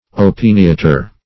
Opiniatre \O`pin*ia"tre\, a.